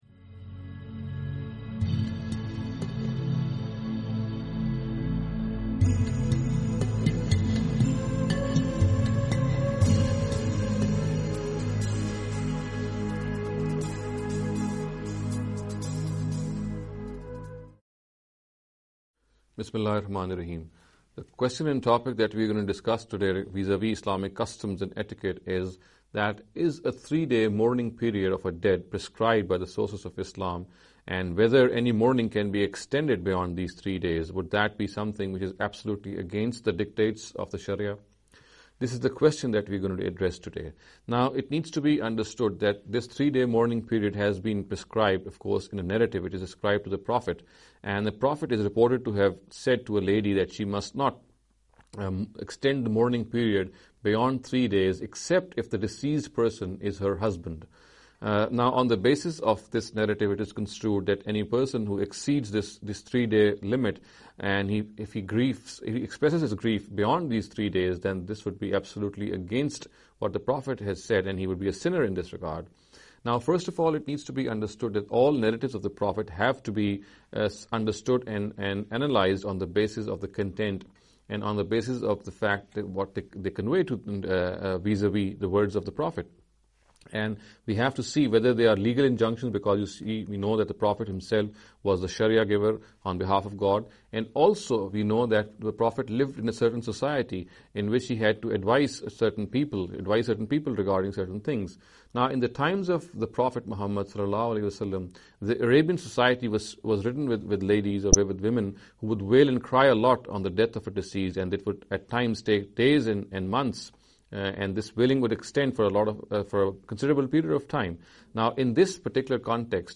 This lecture series will deal with some misconception regarding the Islamic Customs & Etiquette.